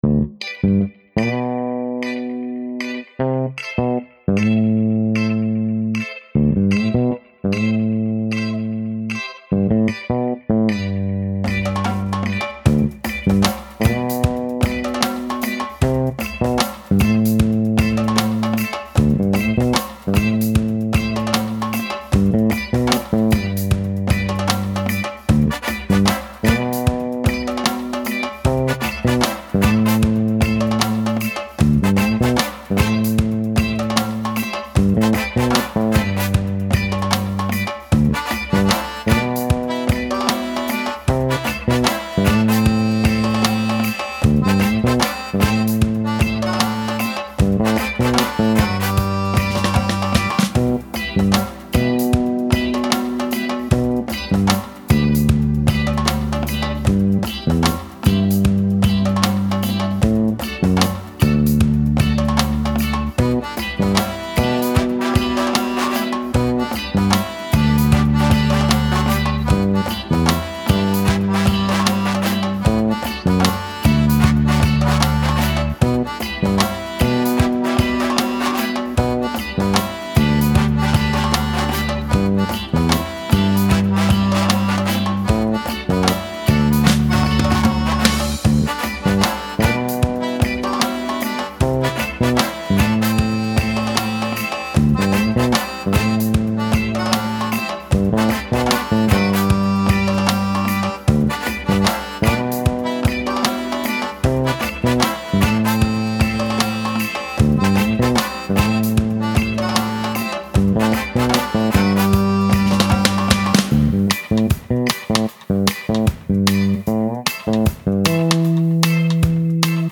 Style Style World
Mood Mood Bright, Cool, Relaxed +1 more
Featured Featured Accordion, Bass, Brass +4 more
BPM BPM 152